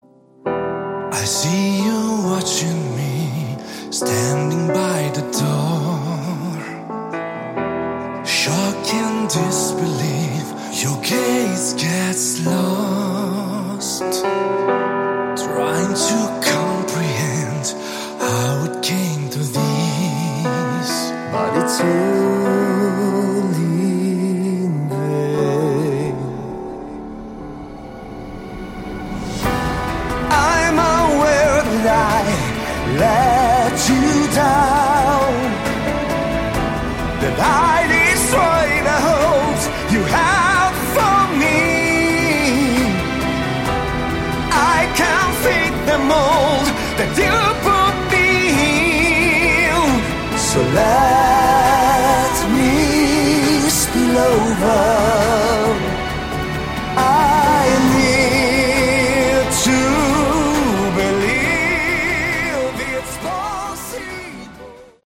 Category: Melodic Metal
vocals
drums
keyboards
guitars, bass